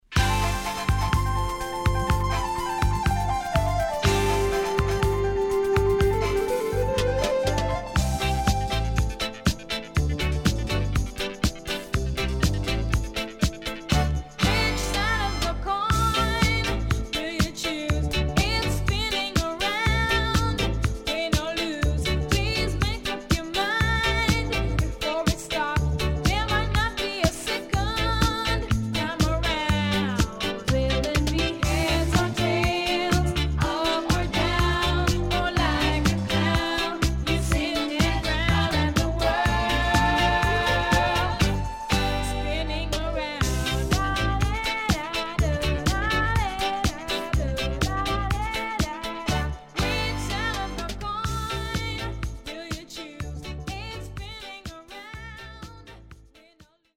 80年全曲Version接続のLong Mix!Superb Lovers Album
SIDE B:少しノイズ入りますが良好です。